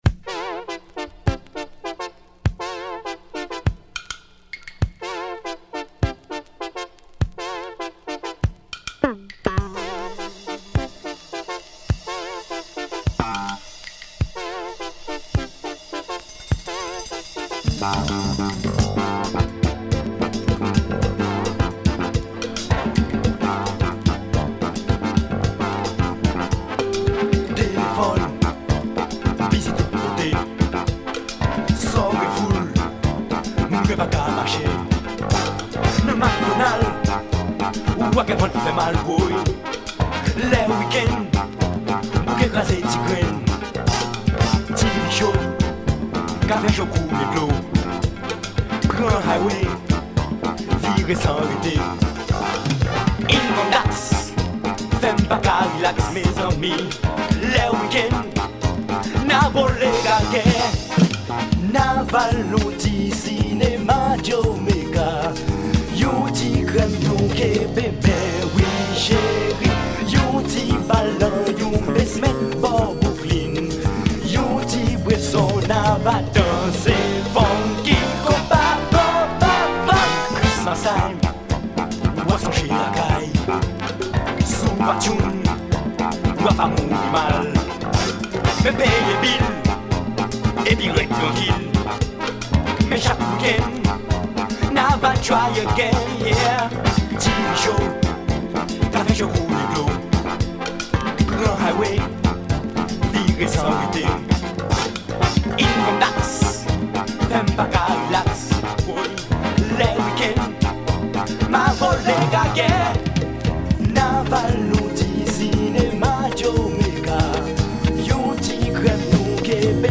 Heavy West Indian funk & zouk with synthesizers.